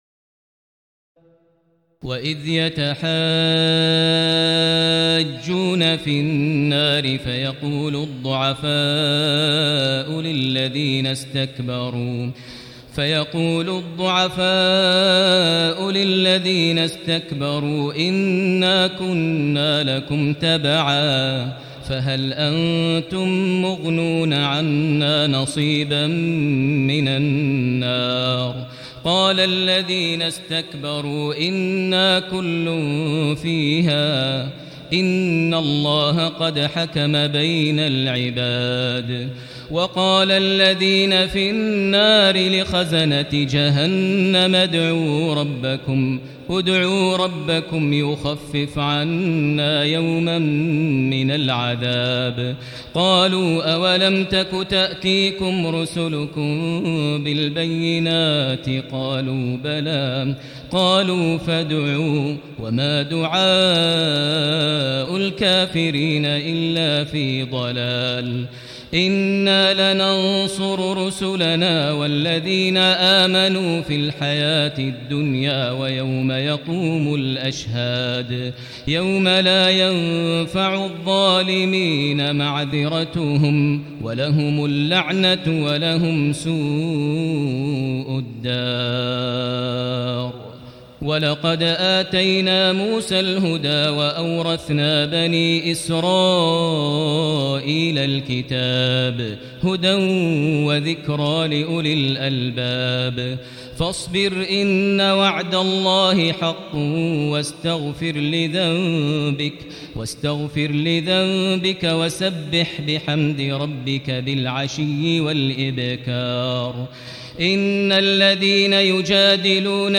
تراويح ليلة 23 رمضان 1439هـ من سور غافر (47-85) وفصلت (1-46) Taraweeh 23 st night Ramadan 1439H from Surah Ghaafir and Fussilat > تراويح الحرم المكي عام 1439 🕋 > التراويح - تلاوات الحرمين